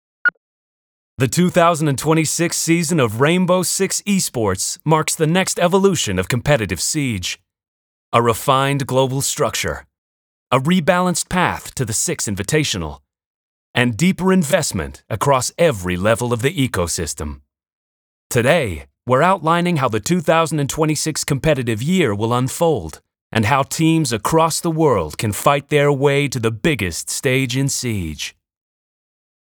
Rainbow 6 Esports_US Accent